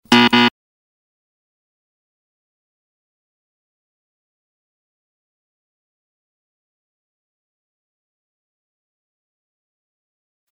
Звуки правильного и неправильного ответа
Звук невірної відповіді (сто до одного)